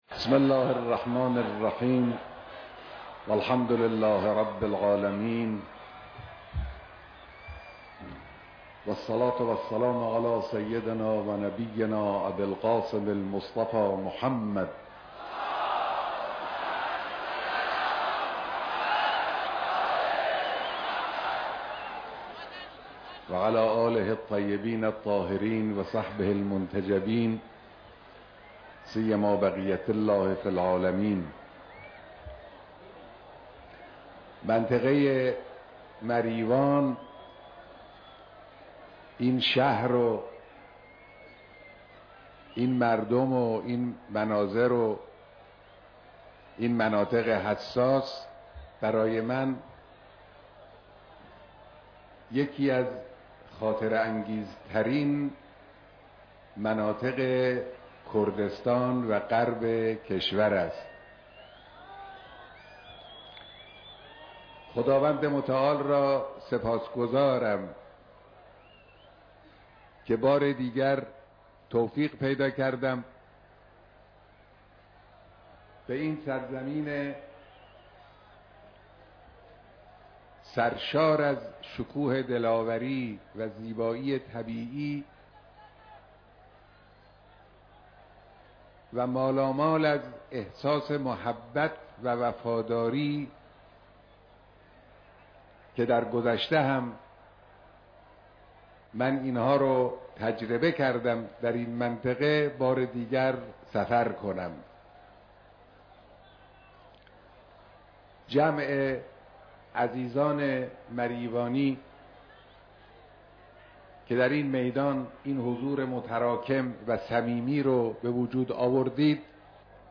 اجتماع پرشور مردم مؤمن، پرمحبت و دلاور مریوان